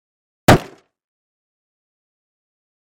На этой странице собраны реалистичные звуки пластиковых дверей: от плавного открывания до резкого захлопывания.
мощный удар ногой по пластиковой двери